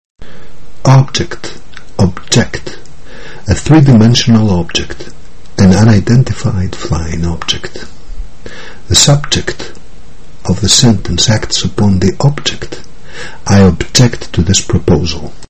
Προσοχή όμως στον διαφορετικό τονισμό του ρήματος από εκείνον του ουσιαστικού.